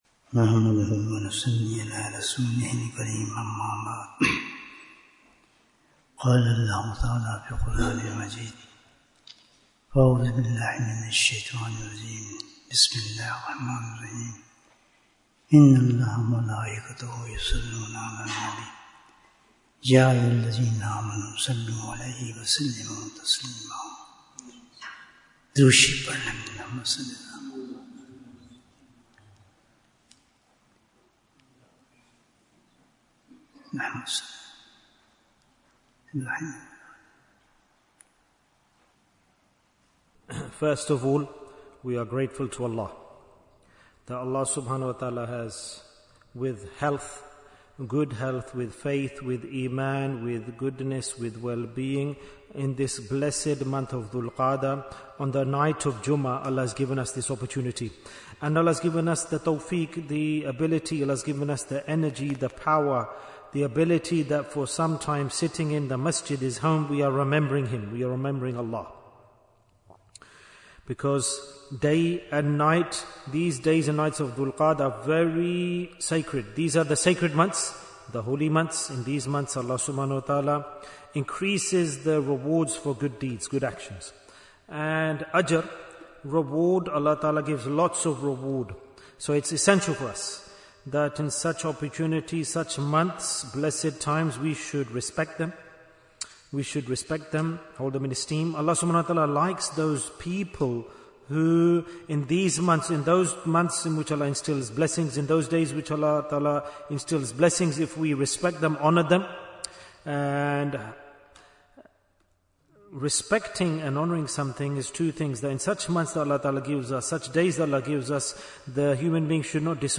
The Message of Surah Ikhlas Bayan, 32 minutes1st May, 2025